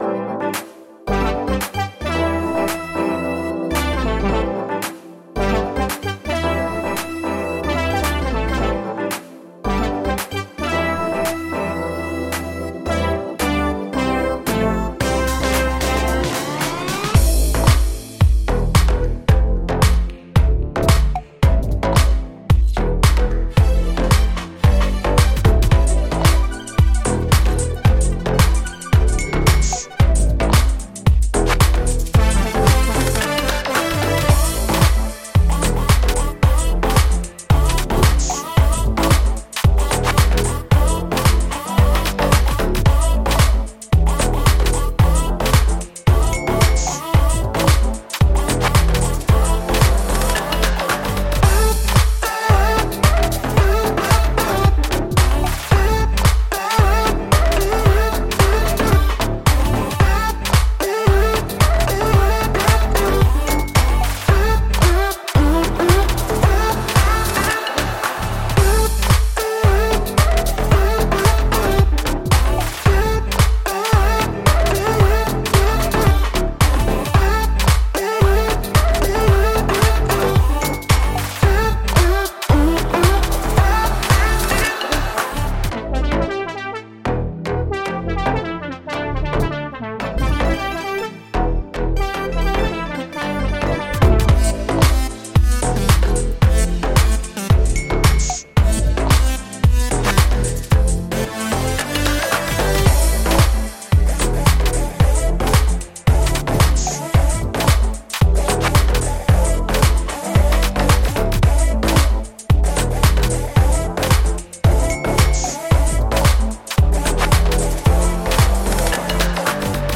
Всем привет,зацените Progressive house.